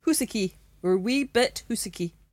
[HOOS-achie: oor wee bit HOOSachie]